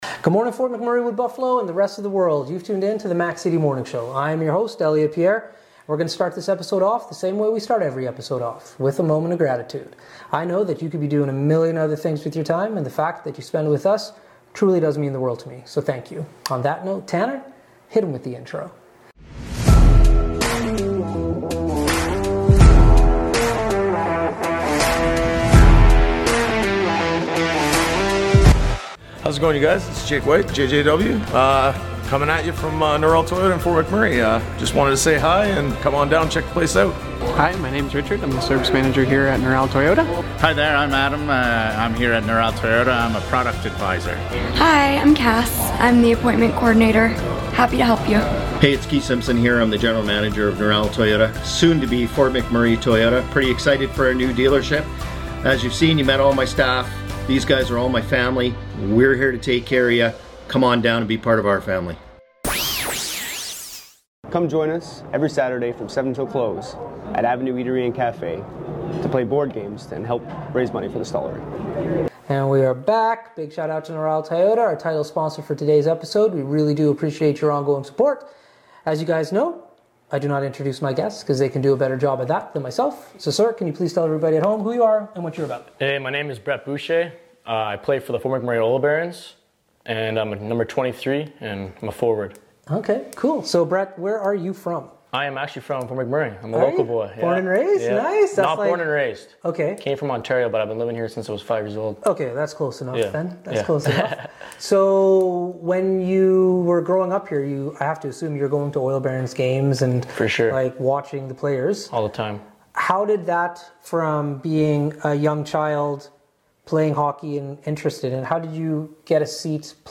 We are back at the Fort McMurray Oil Barons Dream Home this week!